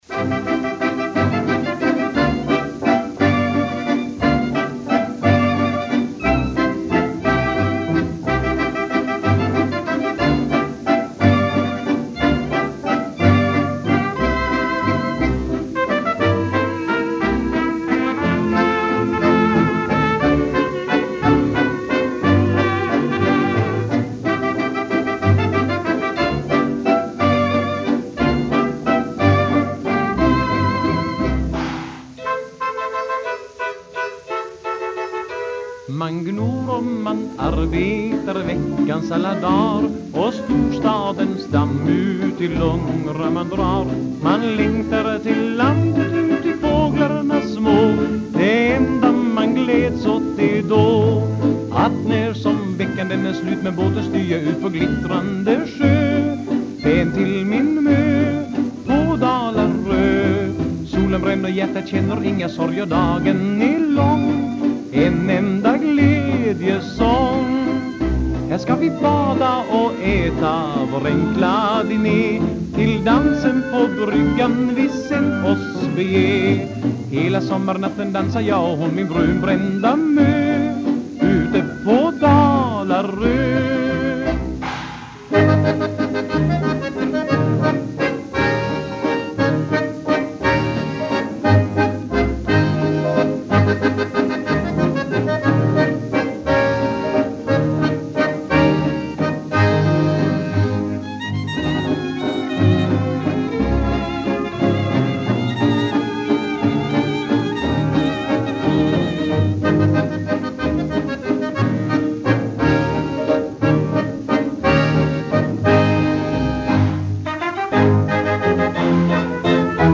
78-varvsskiva
sommarvalsen